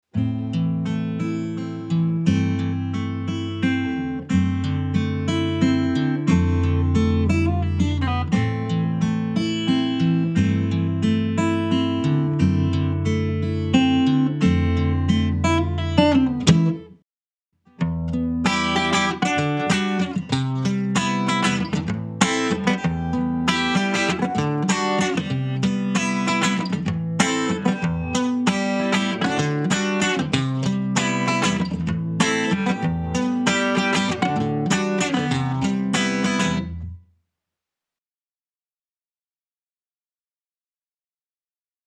Pour ce test, j’ai simplement enregistré un arpège et une rythmique de chaque acoustique modélisée avant et après la mise à jour.
Gibson J-200 Avant MàJ
L’équalization a été légèrement modifée pour certaines guitare mais surtout, la définition du son est meilleure, les guitares ont plus de corps et chacune des cordes des 12 cordes semblent plus perceptibles et réalistes.
1-Gibson-J-200-New.mp3